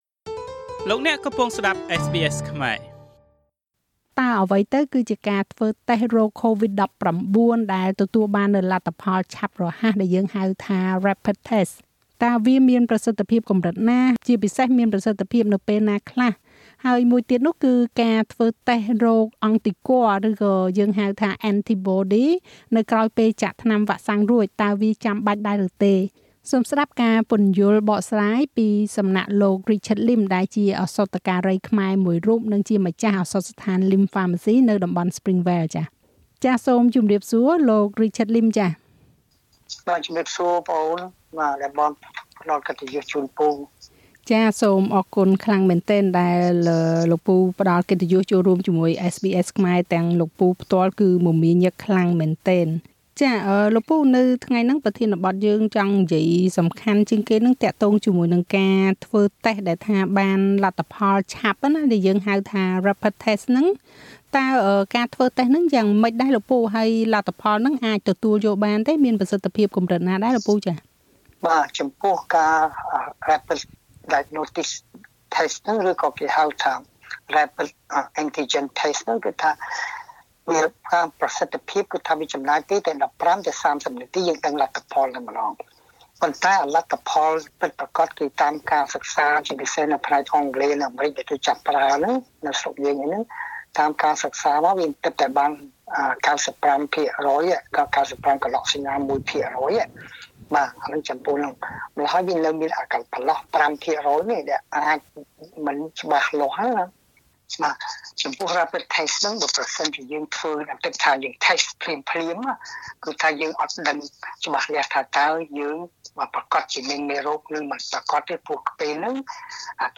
តើយើងគួរចាំបាច់ធ្វើតេស្តរកអង់ទីគ័រ (anti-body test) ដែរឬទេក្រោយពេលចាក់វ៉ាក់សាំងរួច? សូមស្តាប់បទសម្ភាសន៍រវាងSBSខ្មែរ